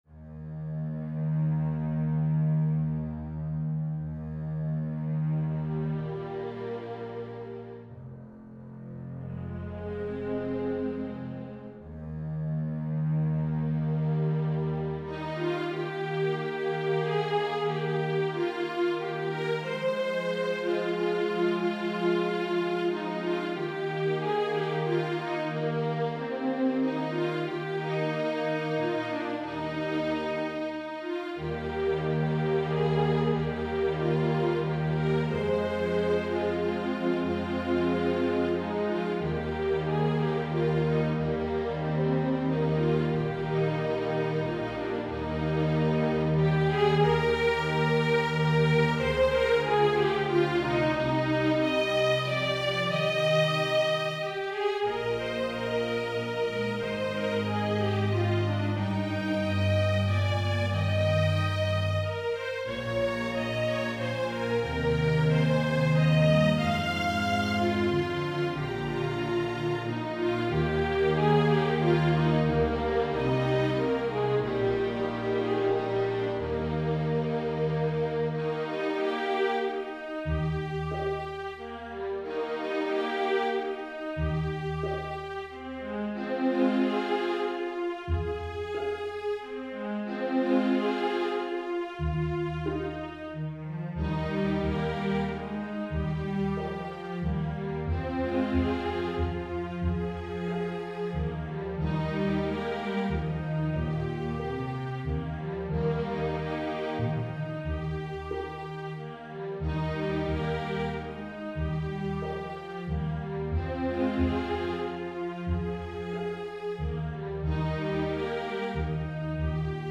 Parts (8-8-5-5-4)
INTERMEDIATE, STRING ORCHESTRA
Notes: mordent, pizz,
Key: E flat major